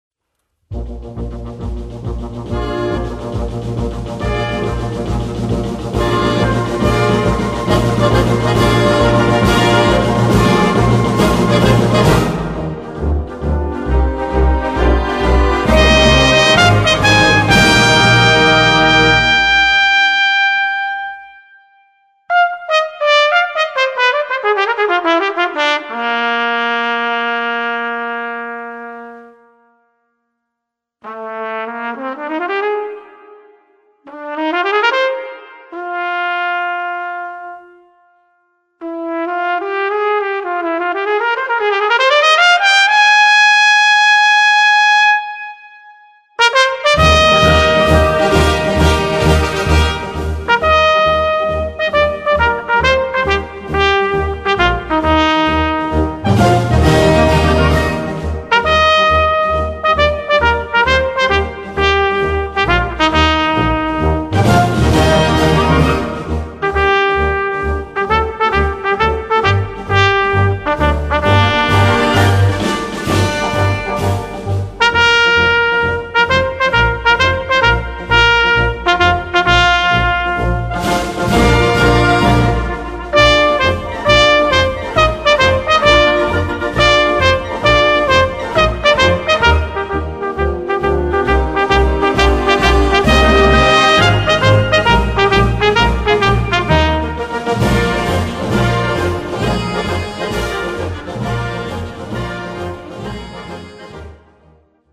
Voicing: Euphonium and Brass Band